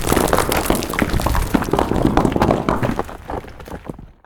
stonet_fall_3.ogg